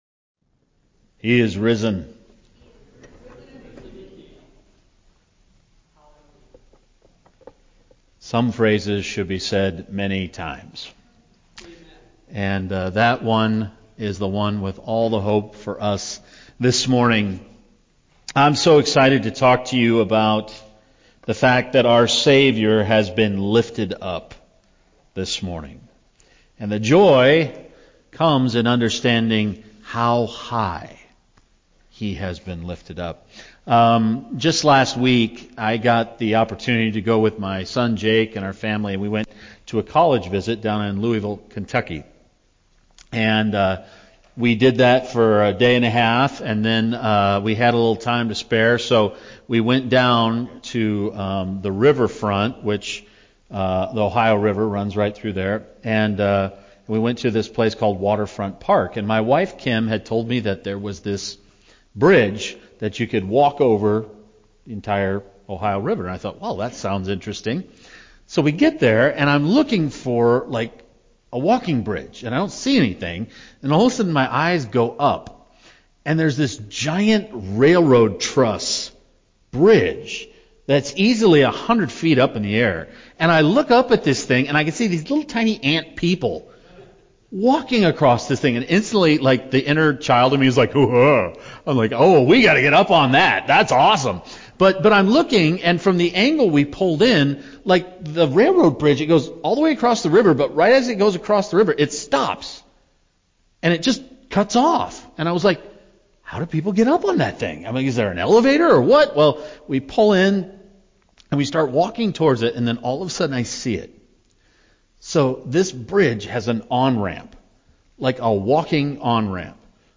Easter morning service